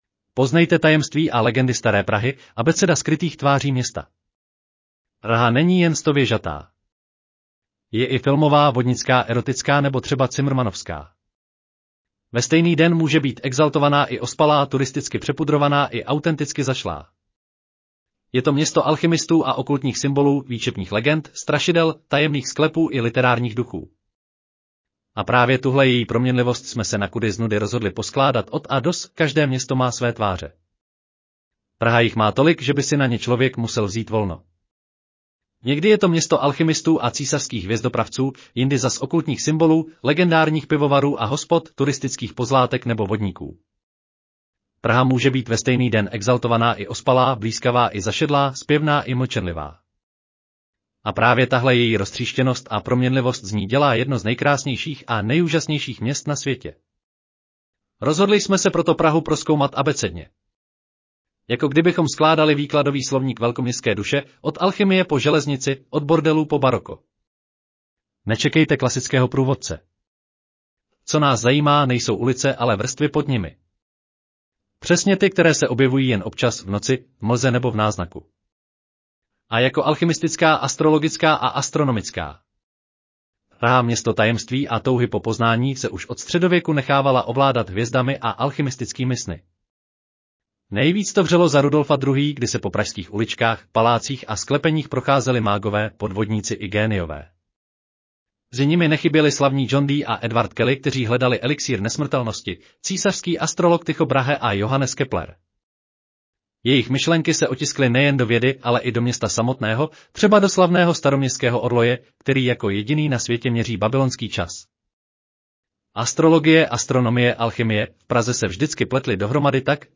Audio verze článku Poznejte tajemství a legendy staré Prahy: abeceda skrytých tváří města